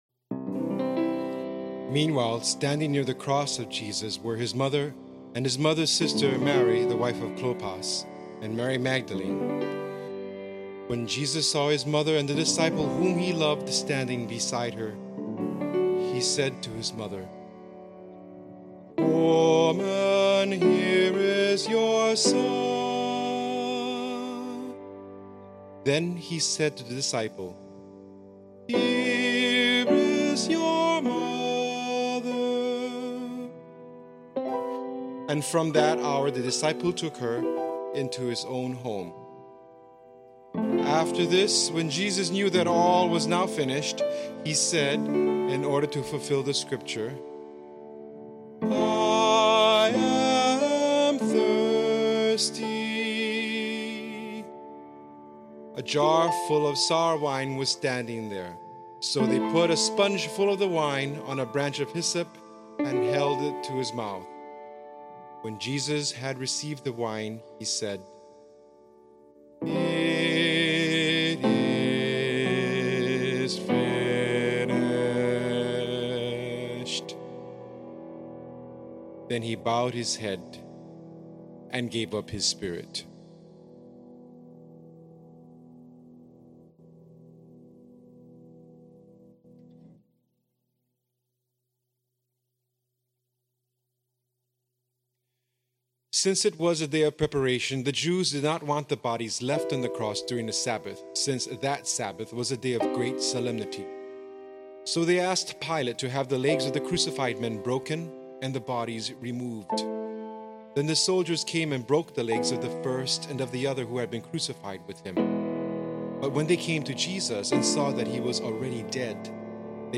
following mp3's are "practice" recordings/score access below